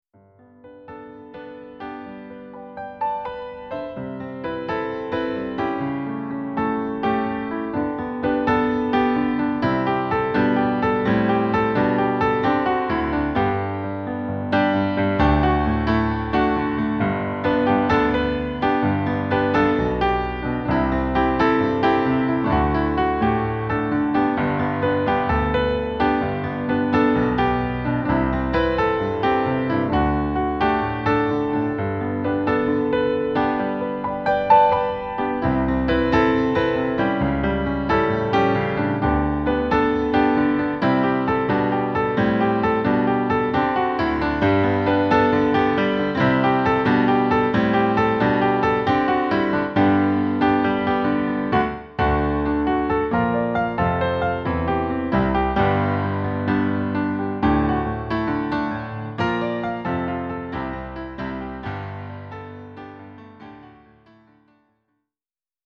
Ihre Trau(m)-Songs wunderschön am Piano gespielt
Hier einige Hörproben des Hochzeits-Pianisten (mp3):